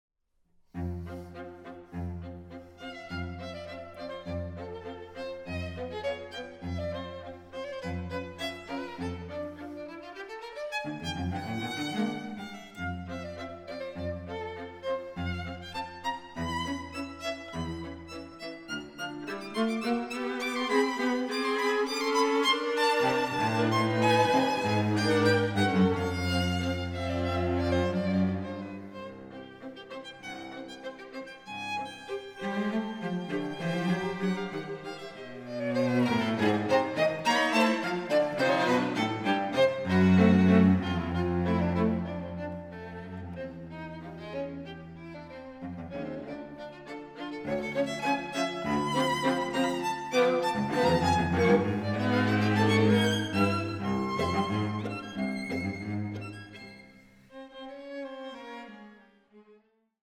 Streichquartett
Aufnahme: Festeburgkirche Frankfurt, 2024